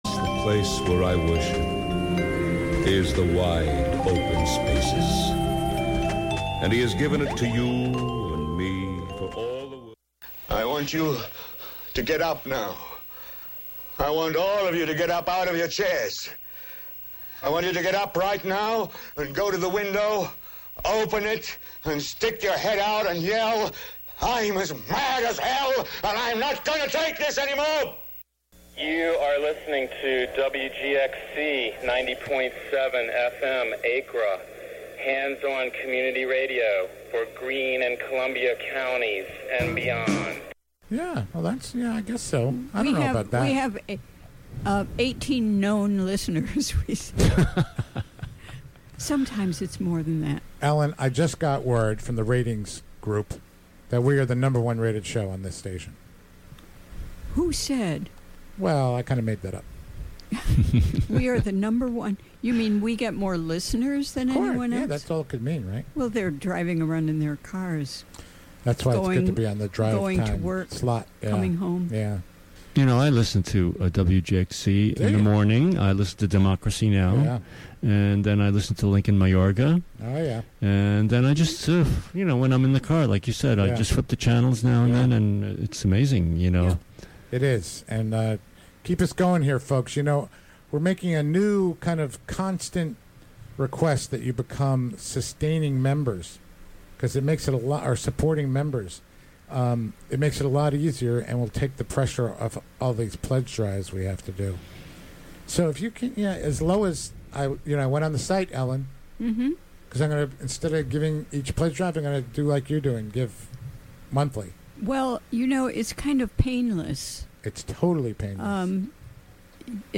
A one-hour call-in program featuring conversations related to medical freedom, national health care, accessibility and related topics. "Medical Freedom" provides a platform and voice for chronic pain patients, advocacy and issues surrounding their care.